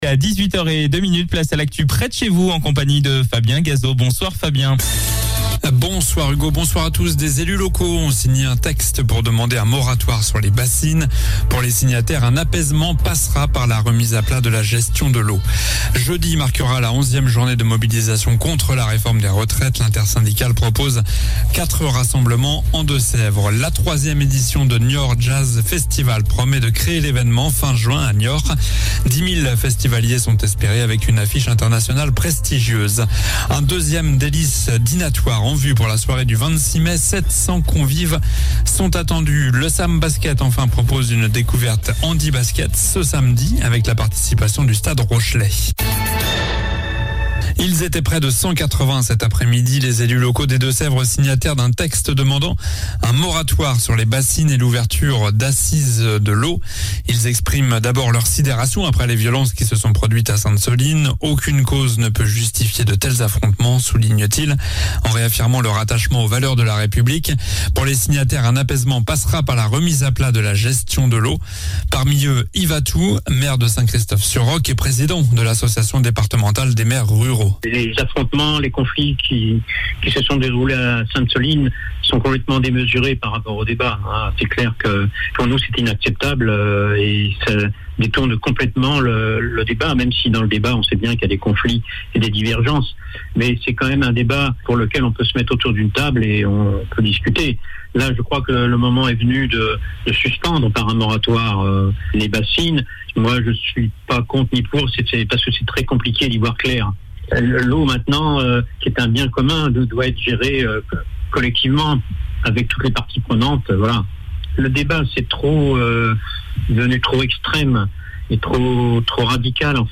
Journal du mardi 04 avril (soir)